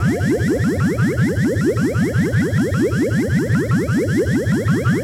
potion_bubbles_brewing_loop_03.wav